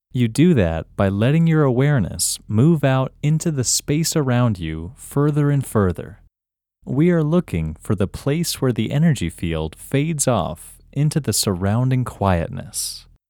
OUT – English Male 6